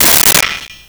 Pot Lid 04
Pot Lid 04.wav